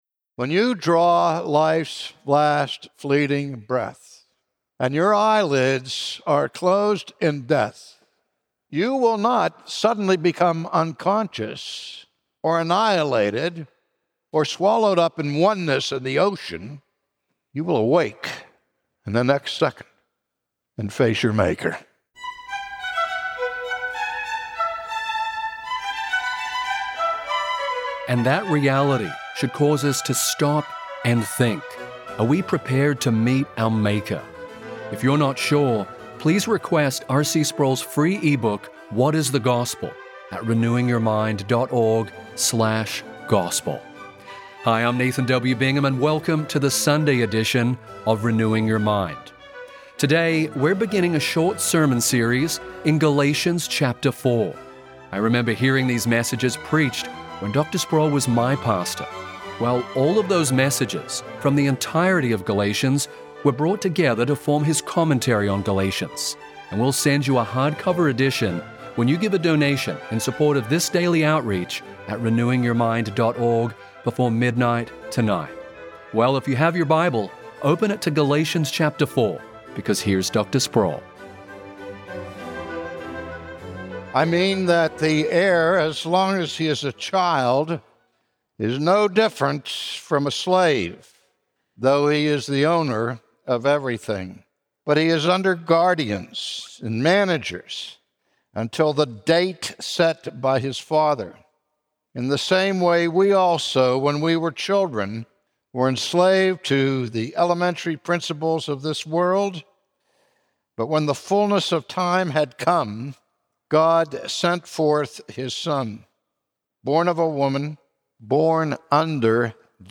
From his sermon series in Galatians, today R.C. Sproul explains what it means to be graciously adopted into the family of God.